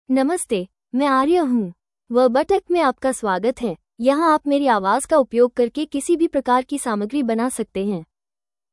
Aria — Female Hindi (India) AI Voice | TTS, Voice Cloning & Video | Verbatik AI
Aria is a female AI voice for Hindi (India).
Voice sample
Listen to Aria's female Hindi voice.
Aria delivers clear pronunciation with authentic India Hindi intonation, making your content sound professionally produced.